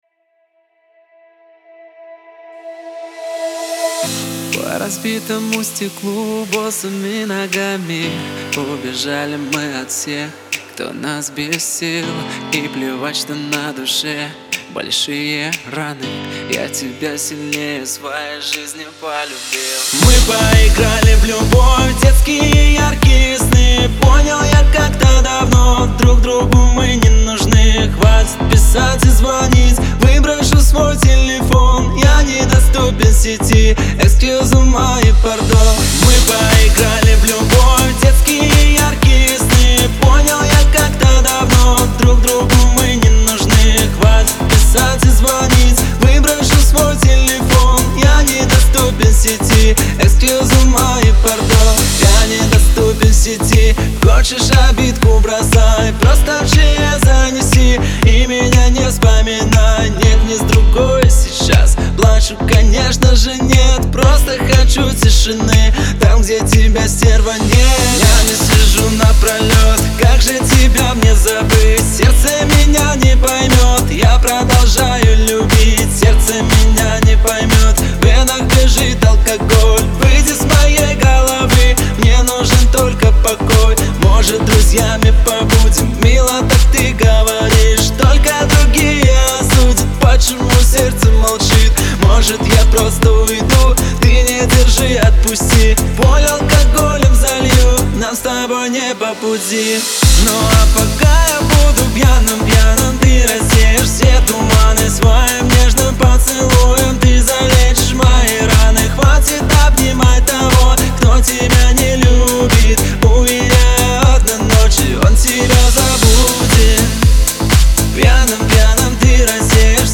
поп-рок